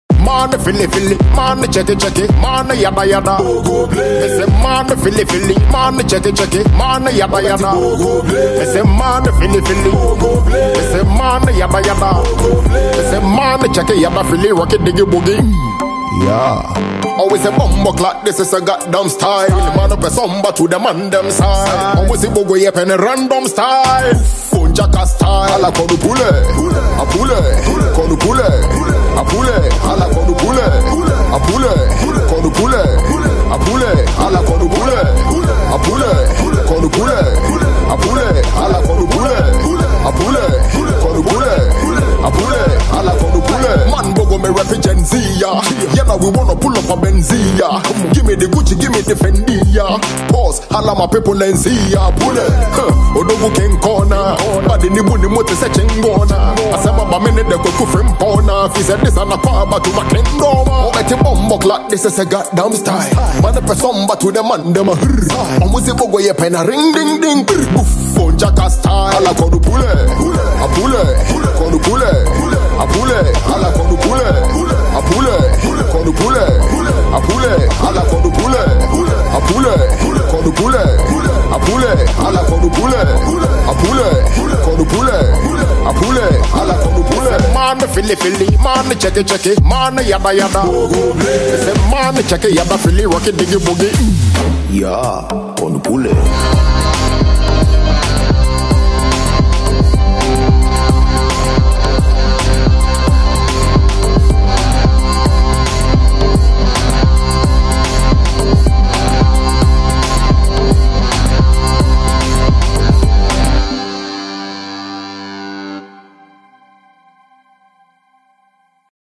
a Ghanaian hip-hop/hip-life rapper